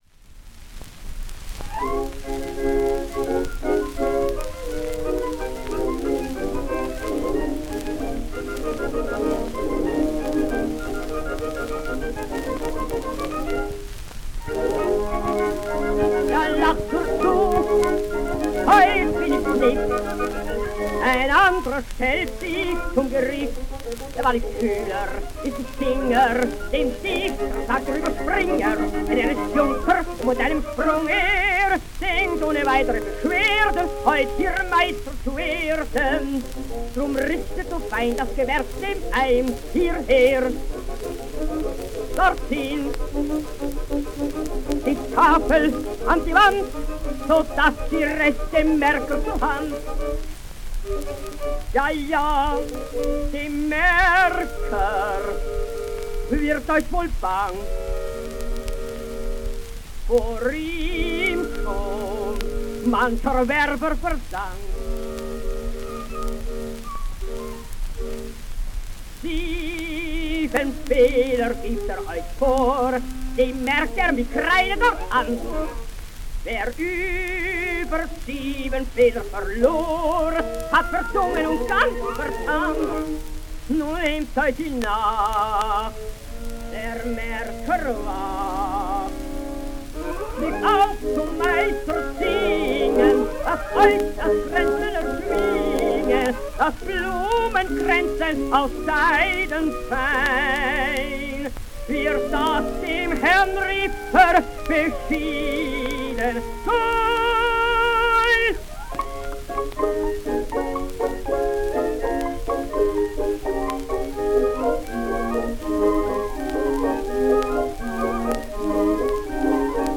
Hans Bechstein singsDie Meistersinger von Nürnberg: